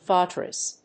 /ˈvəʊtɹɪs(米国英語)/